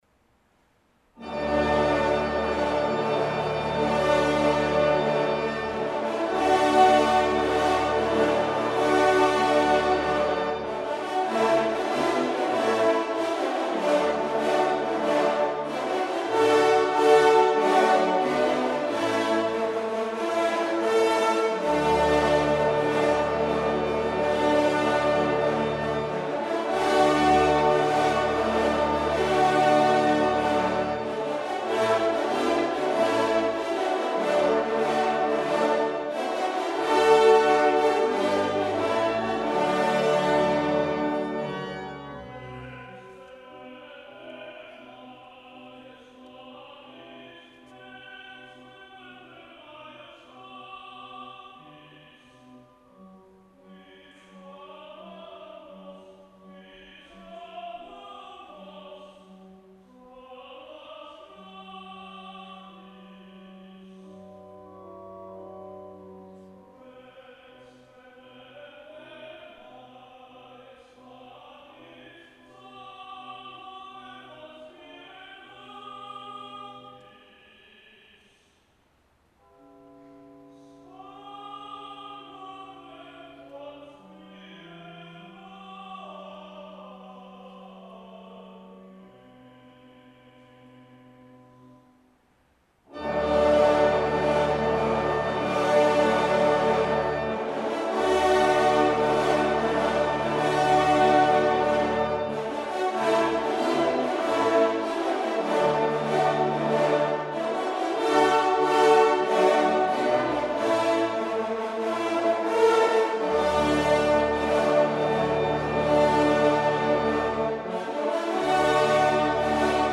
tenor
organ
recorded live at Yale